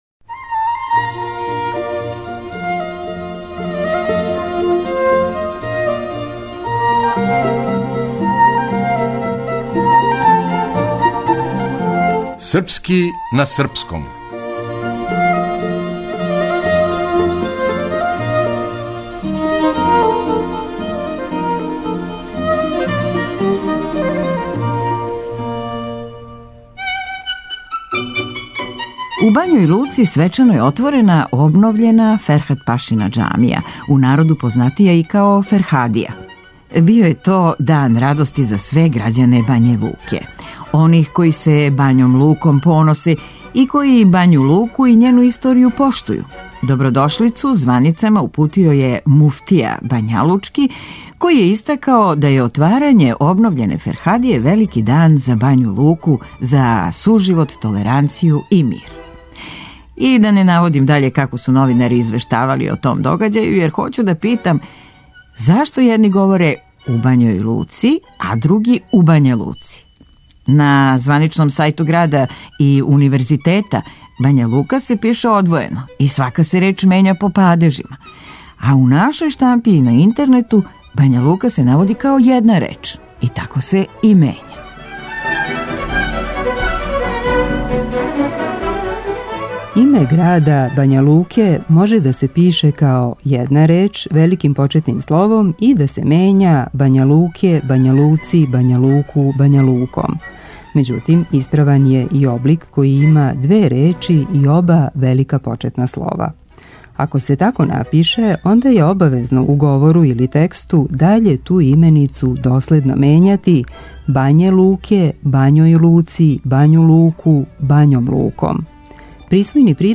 Драмска уметница